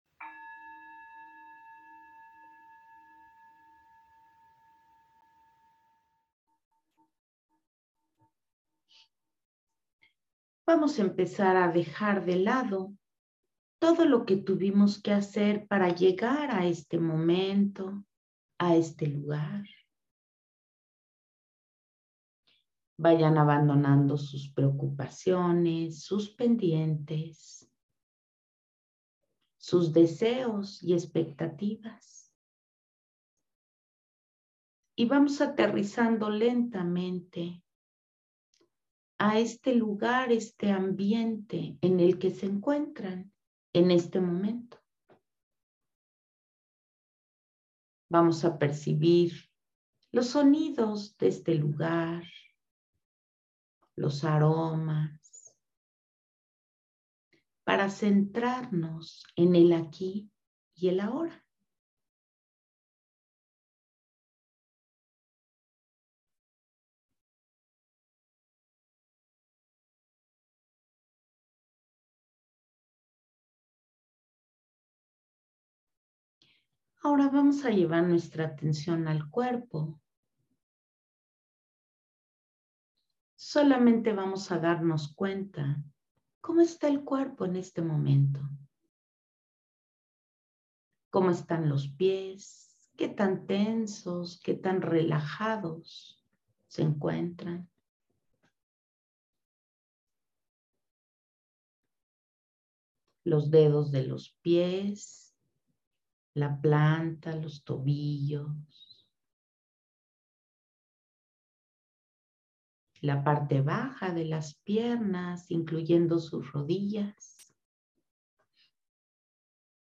Sesión corta de relajacion.mp3